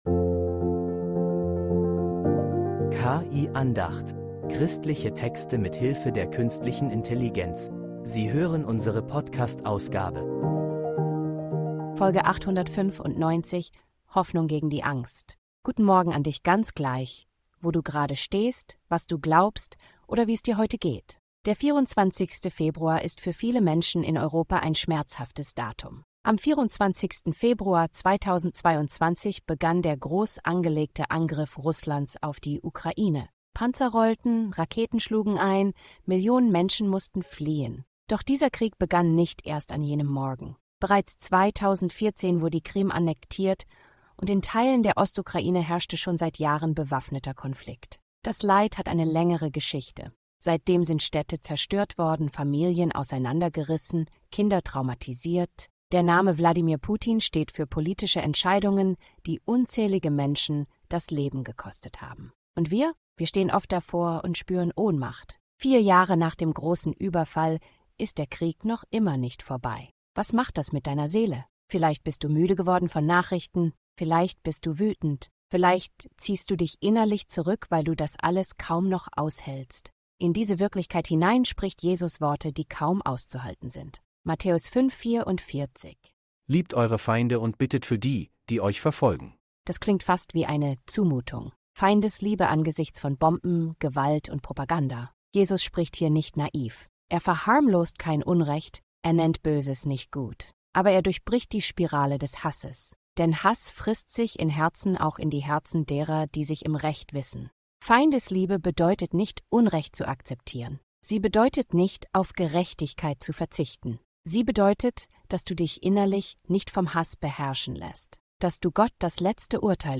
Eine Andacht über Hoffnung, die stärker ist als Angst.